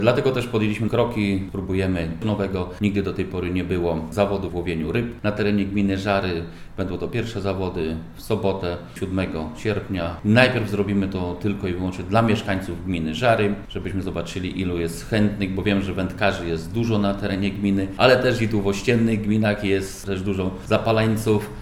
– Dla mnie najważniejsi są moi mieszkańcy. Liczy się człowiek. A takie spotkanie, to sposób na jeszcze większe zacieśnienie relacji – mówi Leszek Mrożek: